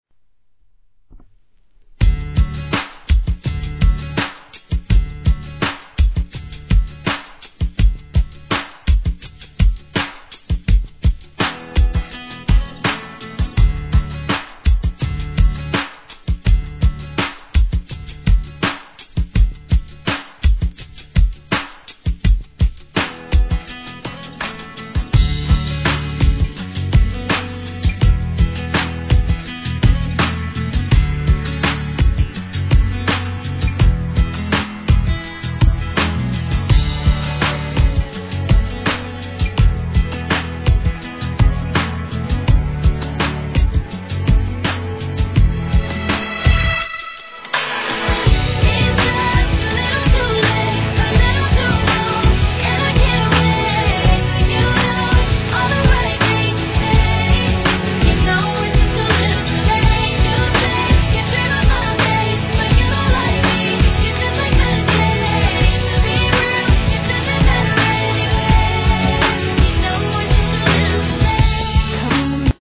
HIP HOP/R&B
トラックを太く差し替えフロア完全対応へと見事に生まれ変わった、この盤限定の激レアリミックスを収録！！